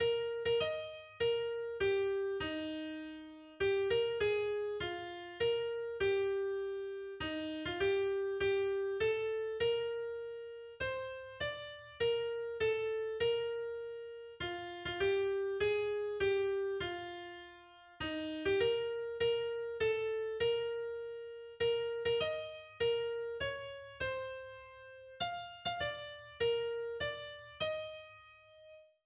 schwäbisches Volkslied